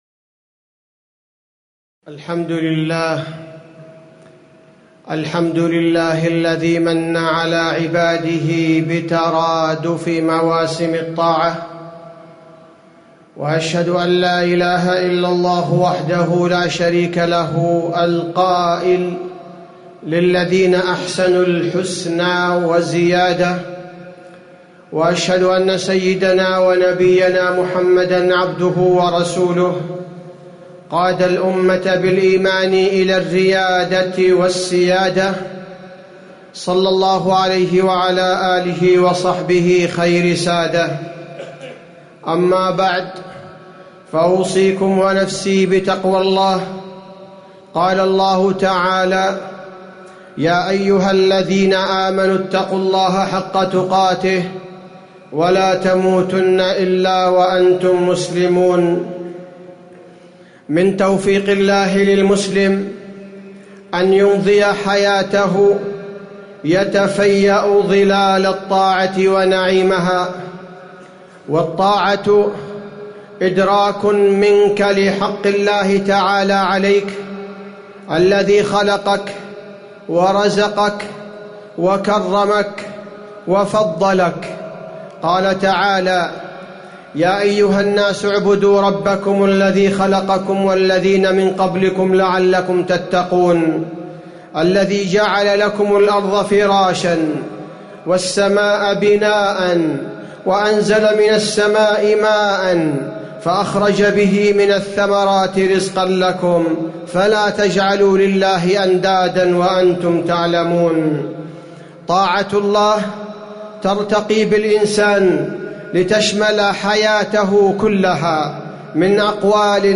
تاريخ النشر ١٠ ذو الحجة ١٤٣٨ هـ المكان: المسجد النبوي الشيخ: فضيلة الشيخ عبدالباري الثبيتي فضيلة الشيخ عبدالباري الثبيتي فضل الطاعة على المسلم